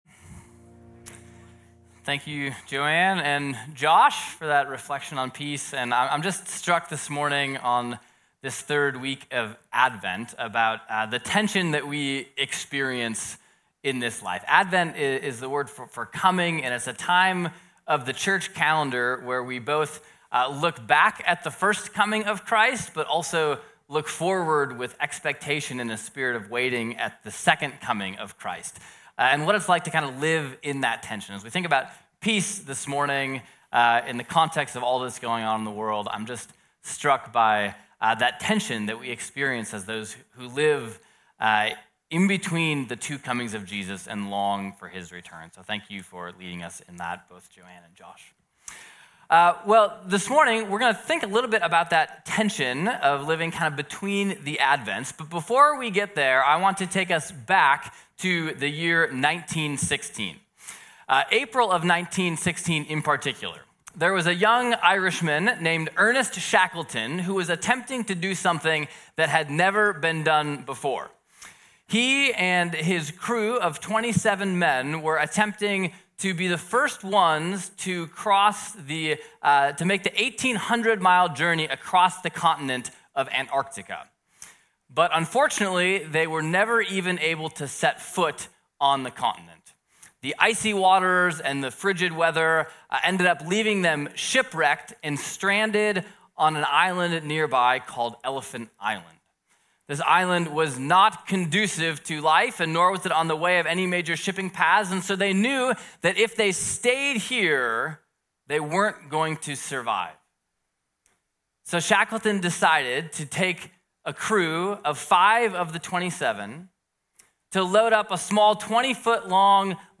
Sermons - Peninsula Bible Church